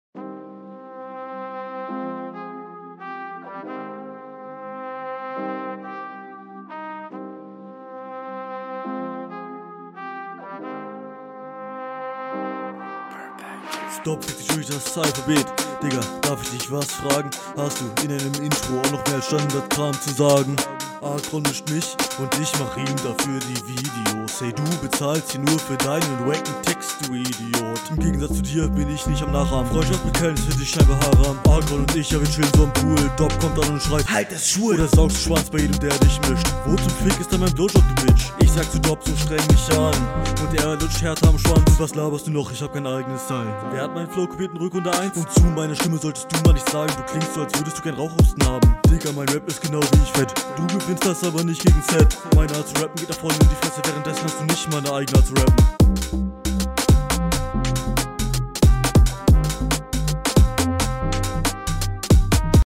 Flow: Yoa auch Grundsolide, aber 1-2 Stellen bisschen vernuschelt, klingt mMn aber schon ein Stückchen …
Flow: Sehr zügig und ist bist mit mehr durchrappen beschäftigt und es ist variation vorhanden …